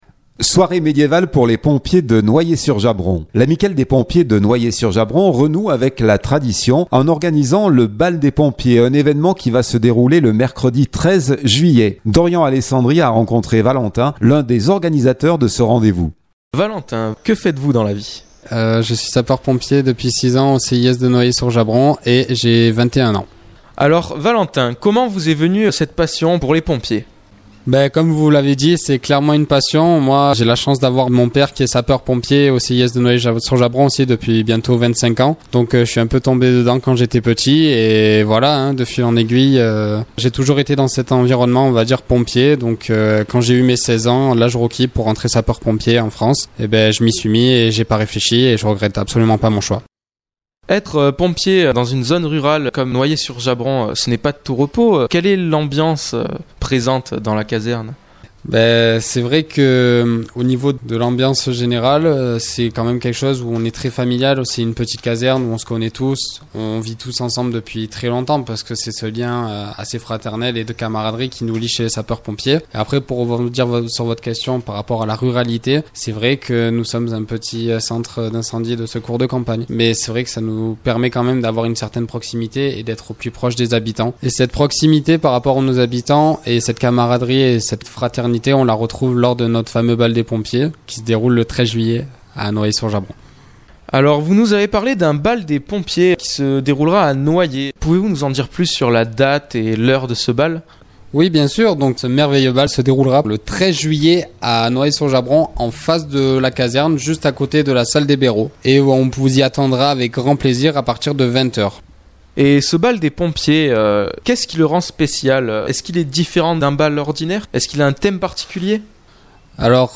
MODULE ANTENNE Bal des Pompiers Noyers-sur-Jabron.mp3 (4.55 Mo)